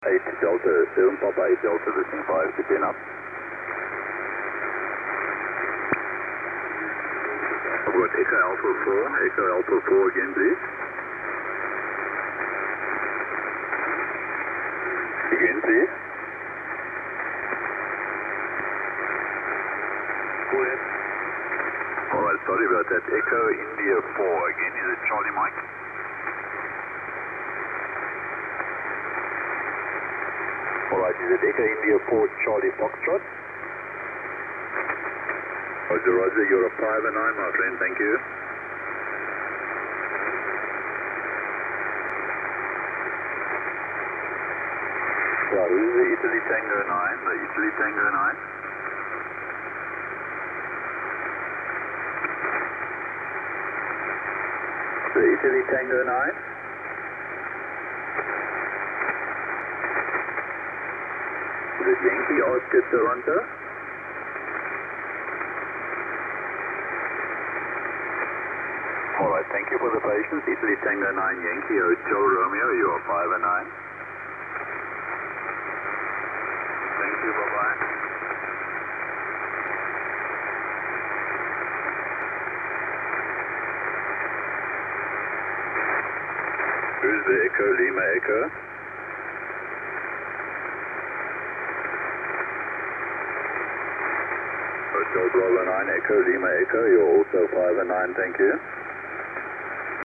ssb